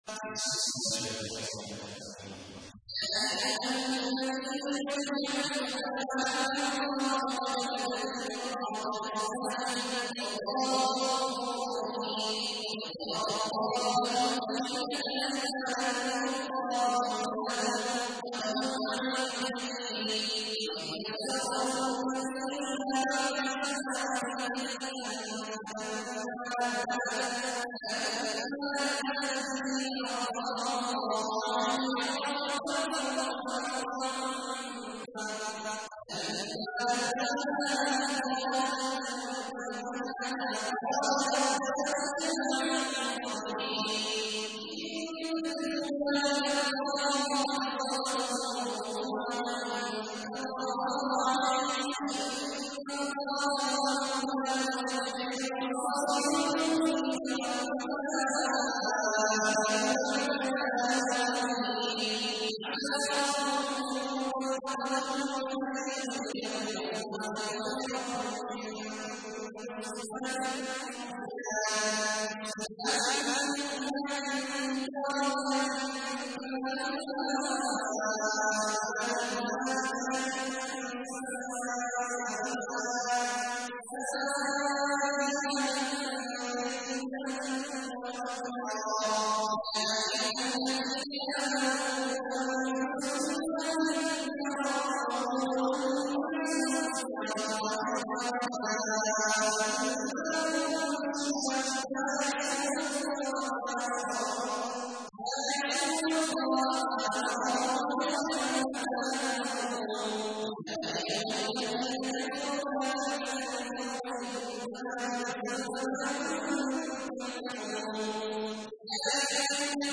تحميل : 66. سورة التحريم / القارئ عبد الله عواد الجهني / القرآن الكريم / موقع يا حسين